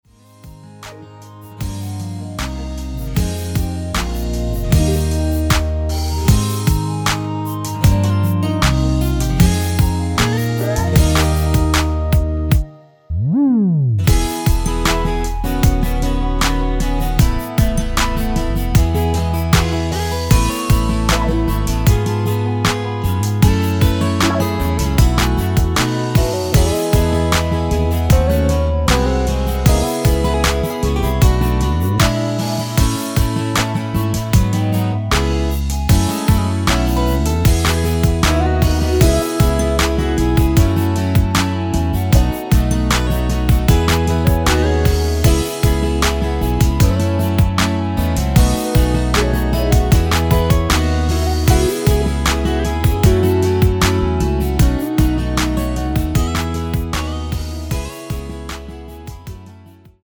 원키 멜로디 포함된 MR입니다.(미리듣기 확인)
Eb
앞부분30초, 뒷부분30초씩 편집해서 올려 드리고 있습니다.